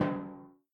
timpani.ogg